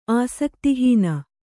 ♪ āsakti hīna